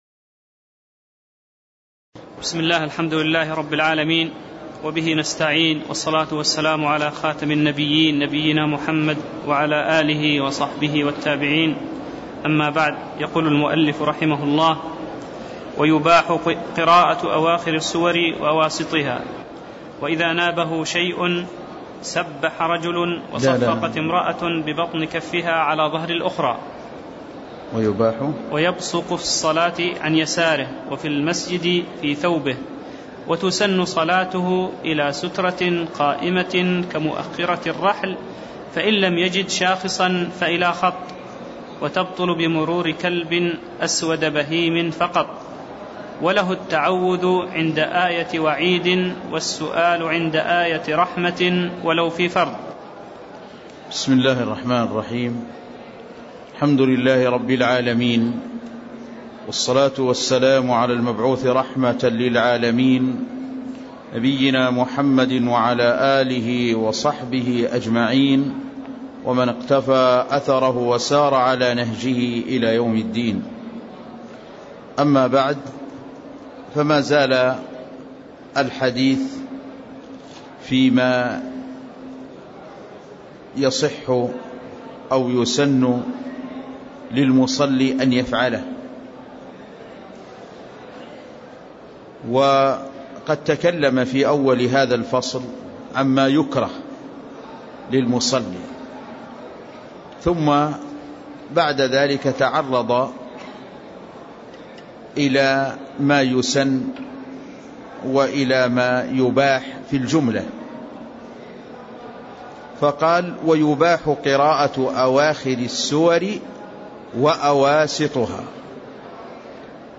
تاريخ النشر ٧ ذو القعدة ١٤٣٥ هـ المكان: المسجد النبوي الشيخ